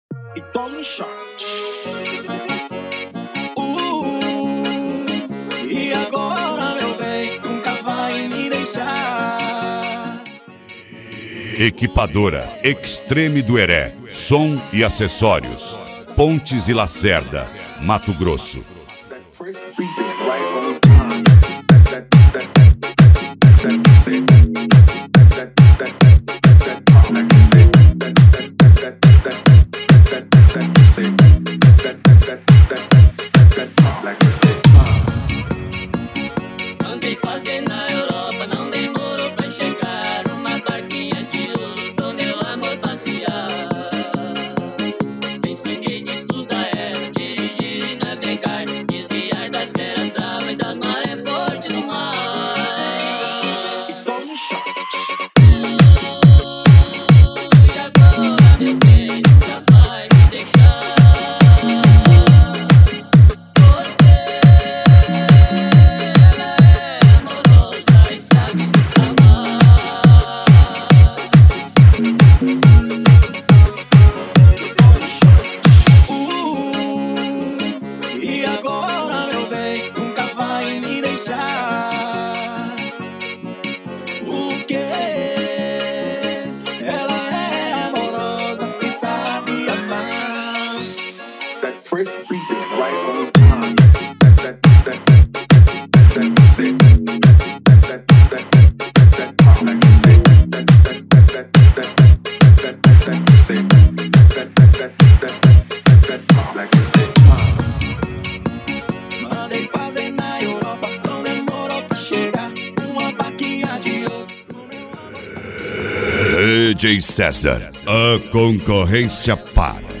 Deep House
Funk
Mega Funk
SERTANEJO